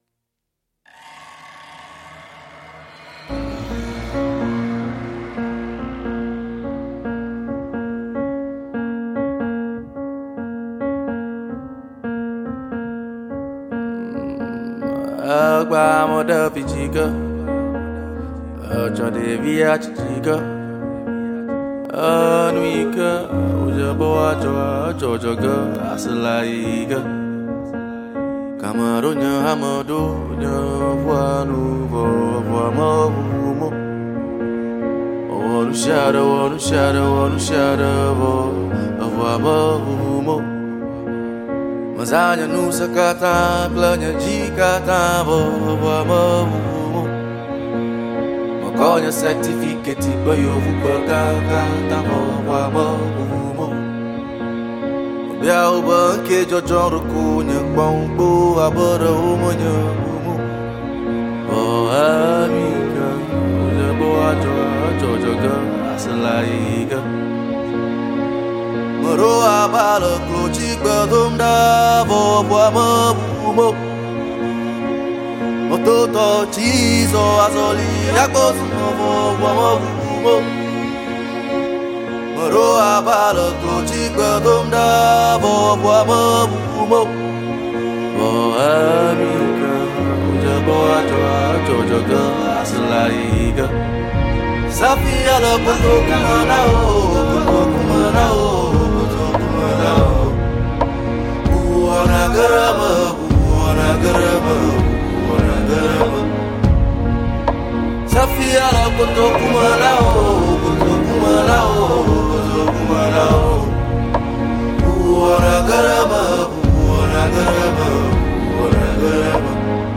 Enjoy this amazing Ghana Afrobeat.